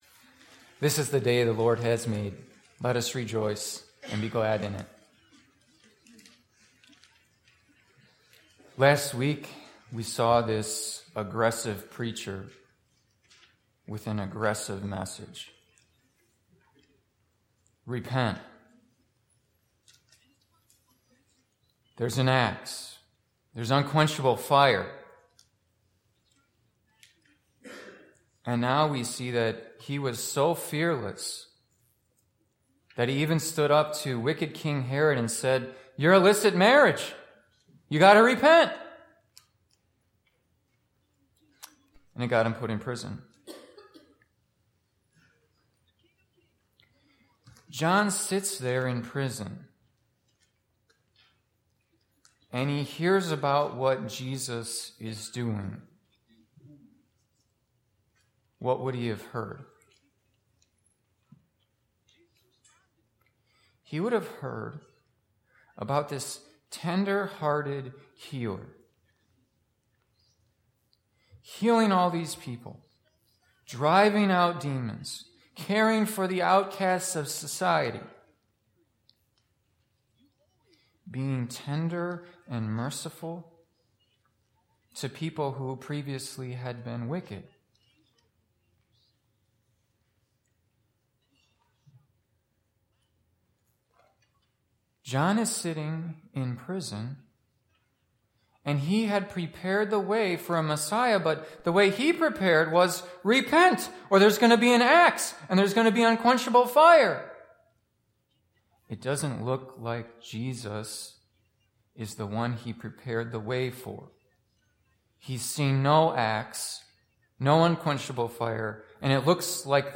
Services (the most recent service is in the first box) Imminent Arrival of the Messiah